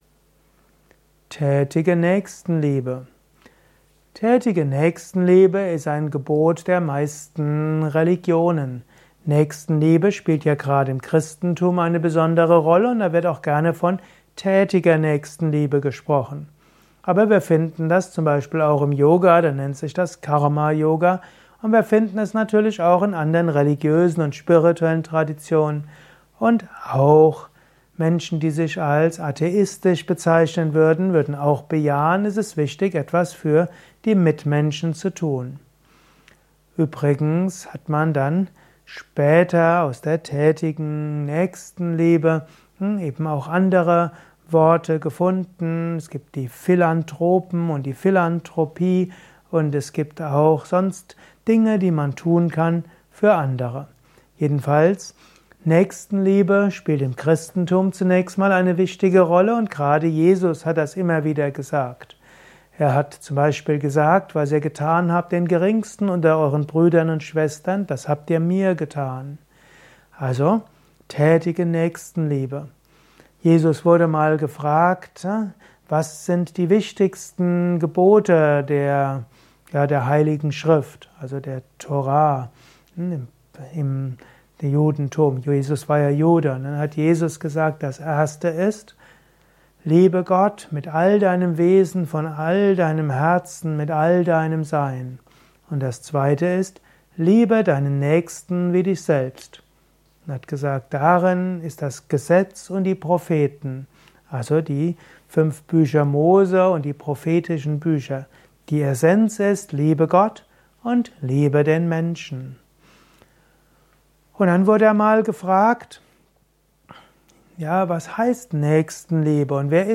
Dieser Vortrag ist Teil des Liebe Podcasts